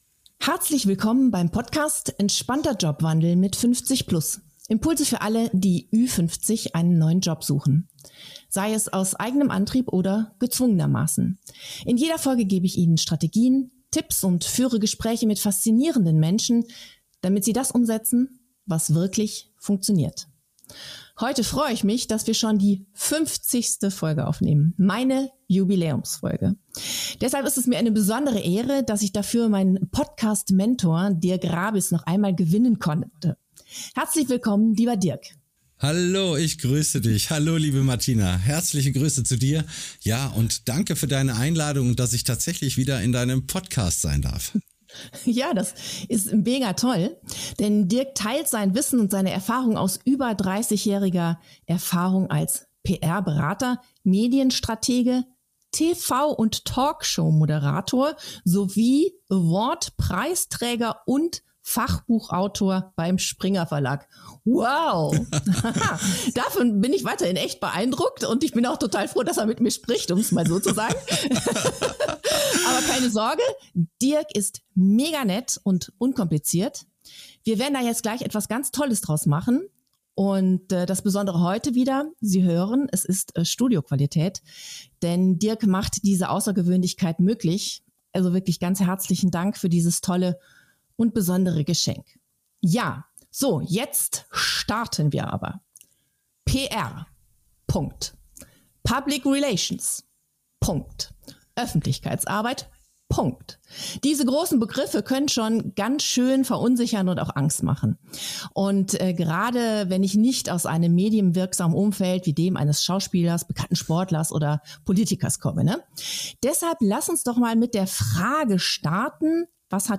Interviewpartner